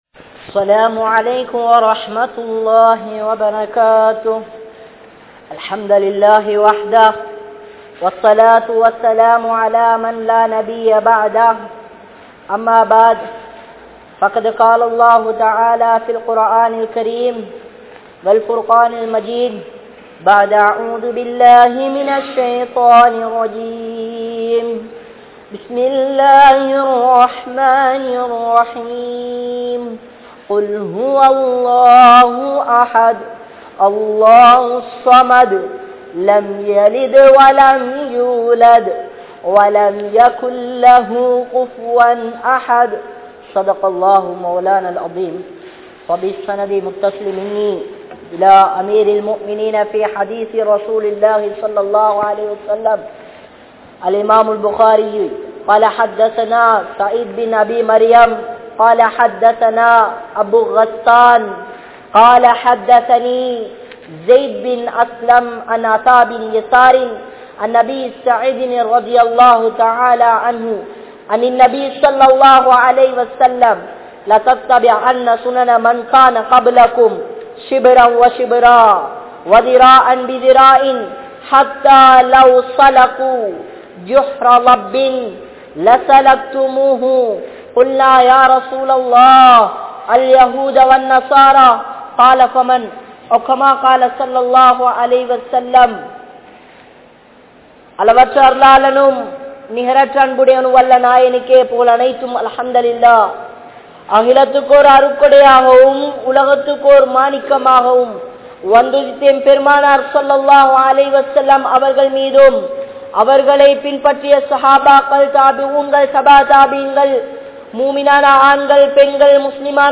Dua & Sadhaqa | Audio Bayans | All Ceylon Muslim Youth Community | Addalaichenai